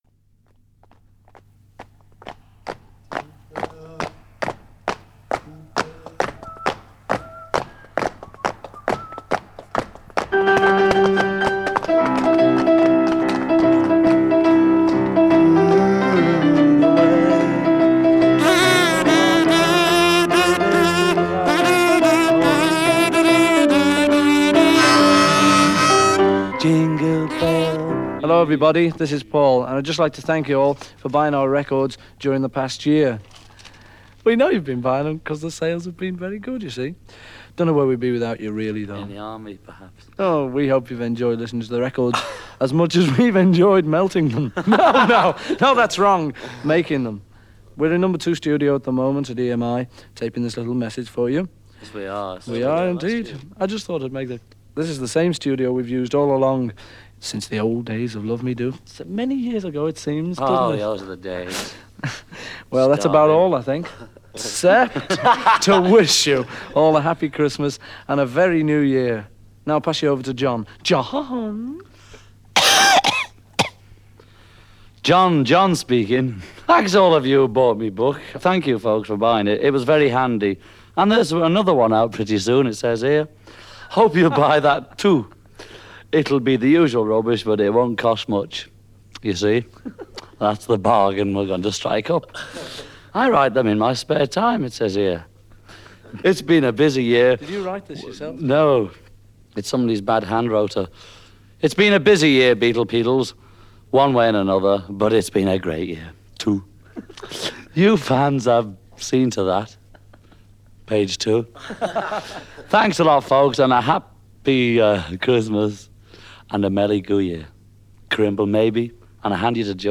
Рождественские записи Битлз делали с 1963 по 1969 годы и гибкие пластинки с ними направлялись в фан-клубы.
(Поют песню «Jingle Bells»):
(Поют английскую народную песню «Oh Can You Wash Your Father’s Shirt?»):